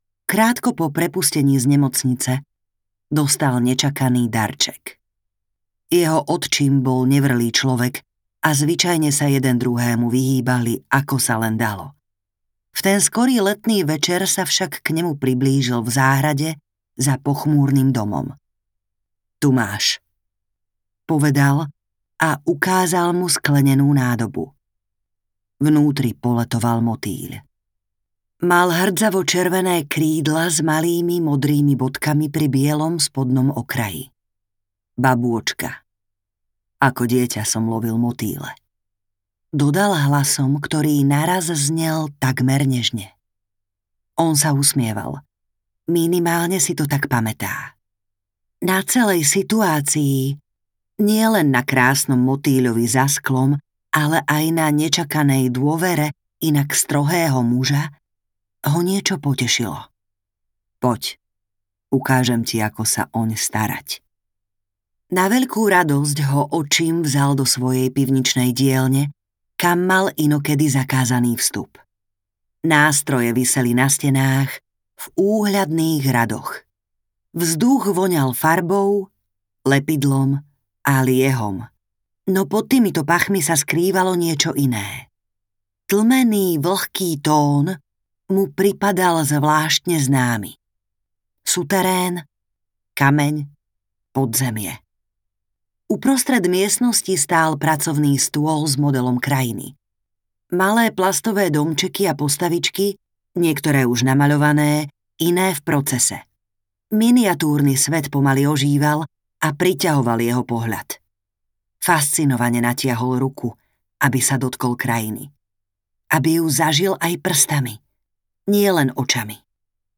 Zmenárnik audiokniha
Ukázka z knihy
zmenarnik-audiokniha